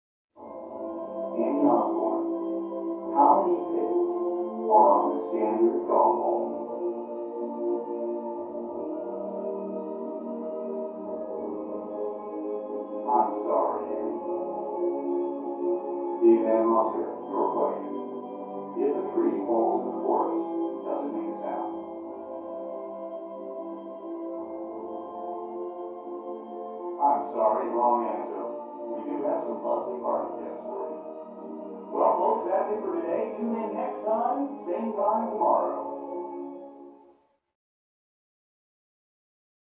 Television; Game Show Questions With Music And Announcer. From Down Hallway.